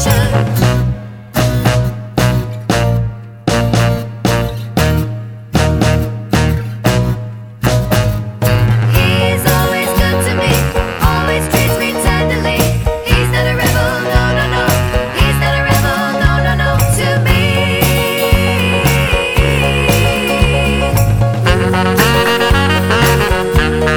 no saxes Soul / Motown 2:16 Buy £1.50